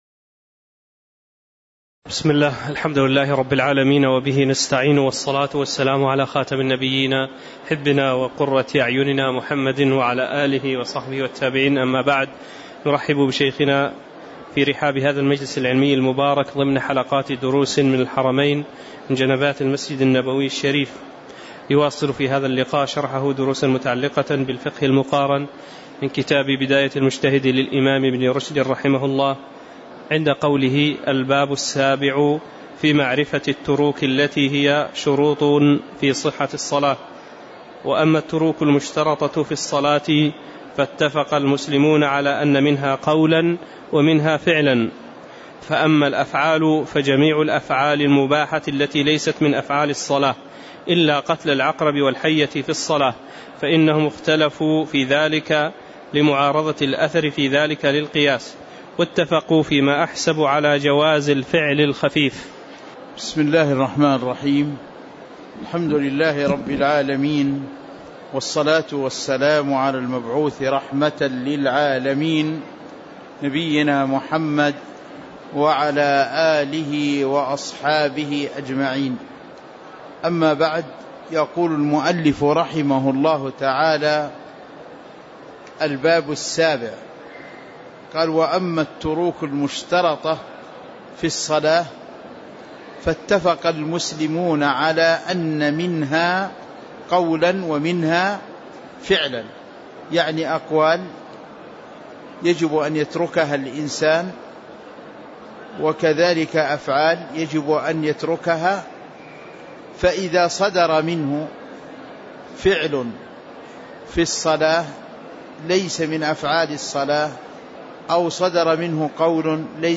تاريخ النشر ٢١ ربيع الأول ١٤٤١ هـ المكان: المسجد النبوي الشيخ